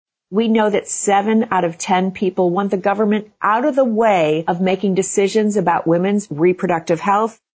Outgoing Senator Jill Schupp of Creve Coeur says most Americans are against the government telling people what they can do with their medical choices: